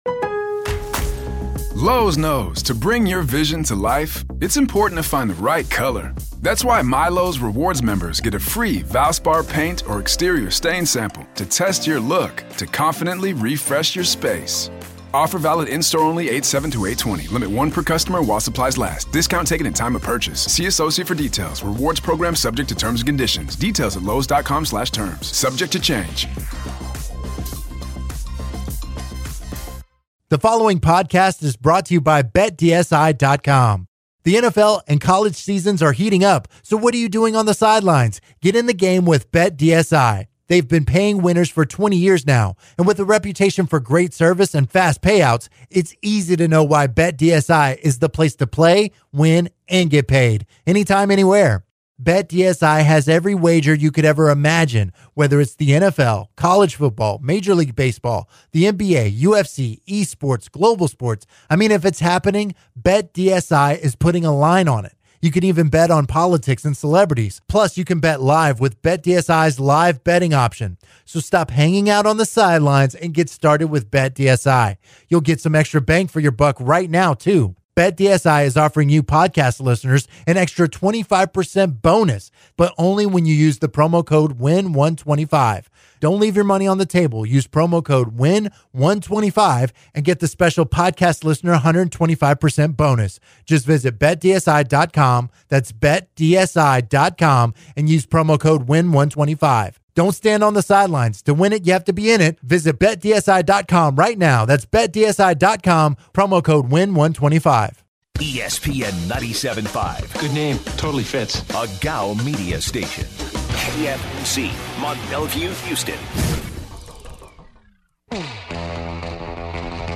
The guys also recapped the Rockets’ season-opener 131-112 loss to the New Orleans Pelicans. They guys take calls from listeners and discuss where blame on game performance is placed between players, coaches, and management.